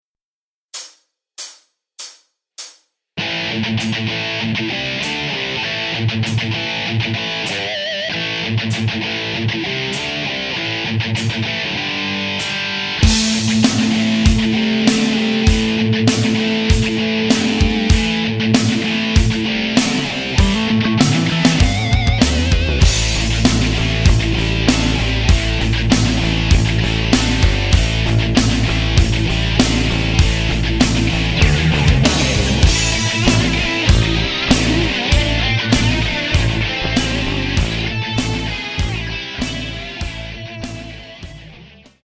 Guitare Tablatures